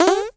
pgs/Assets/Audio/Comedy_Cartoon/cartoon_boing_jump_02.wav
cartoon_boing_jump_02.wav